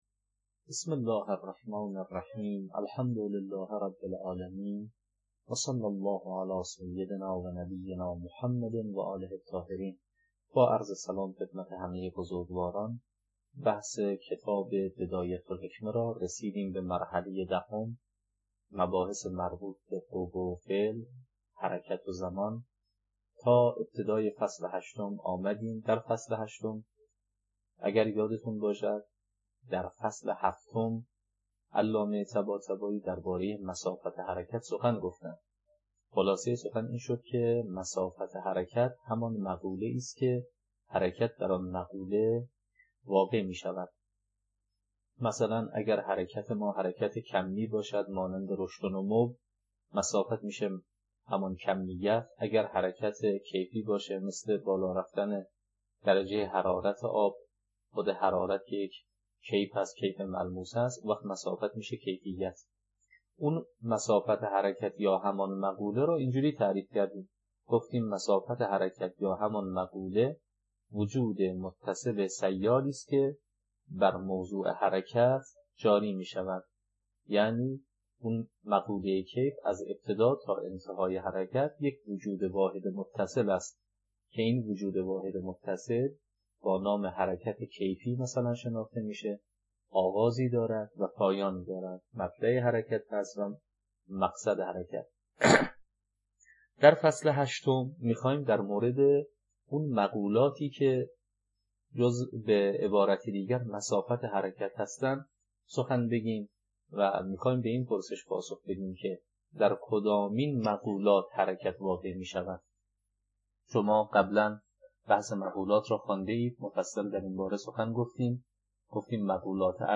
التمهيد في الحكمة الهية (خلاصه بدایه الحکمه) - تدریس